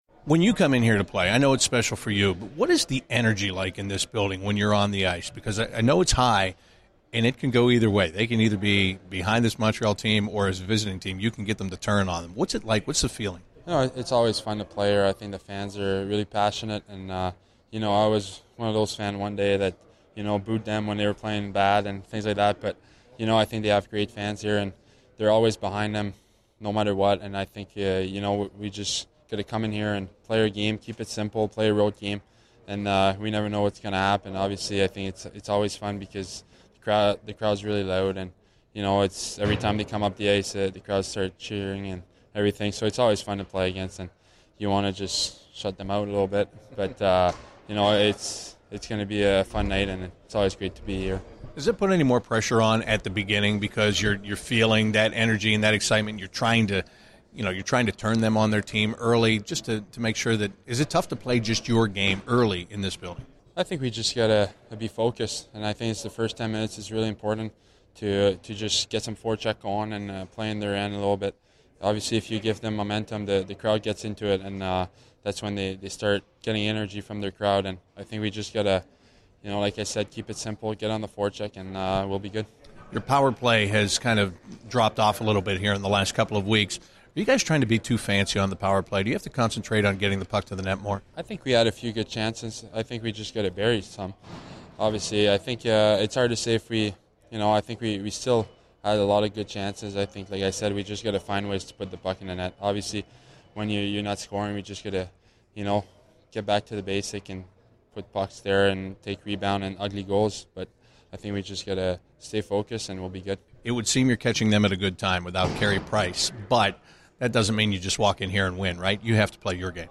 David Savard Pre-Game 12/01/15